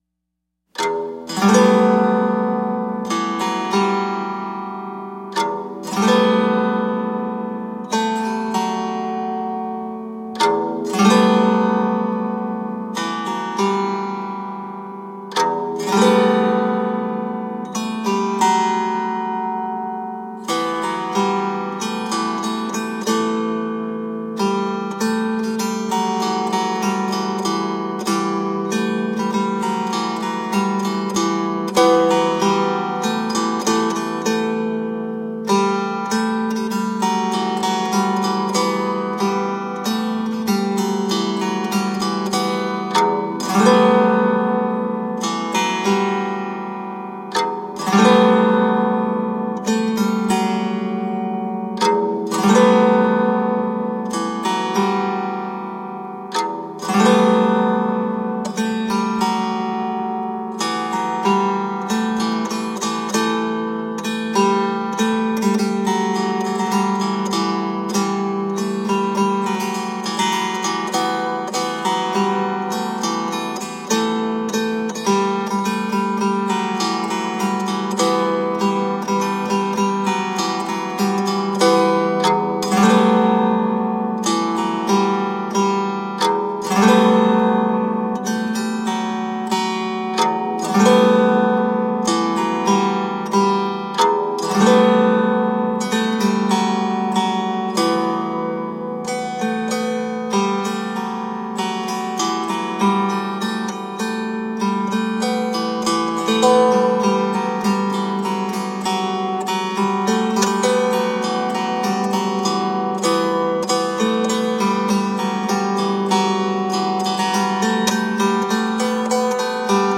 Medieval and middle eastern music.
traditional Latvian